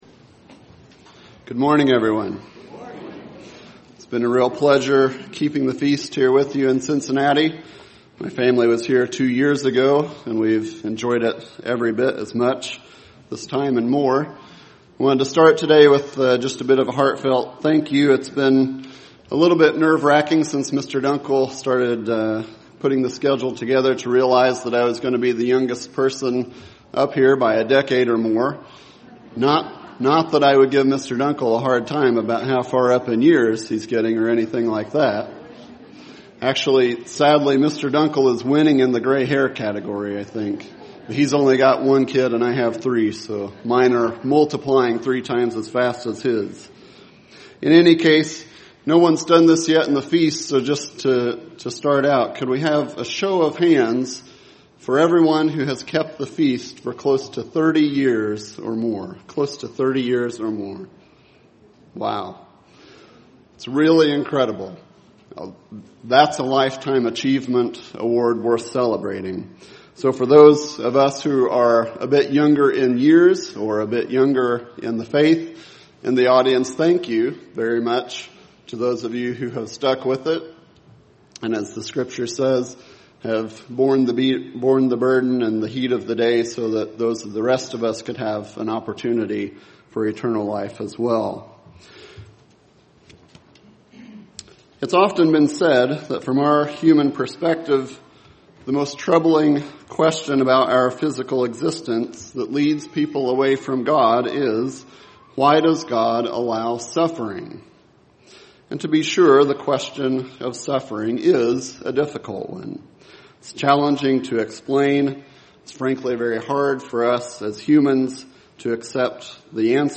This sermon was given at the Cincinnati, Ohio 2015 Feast site.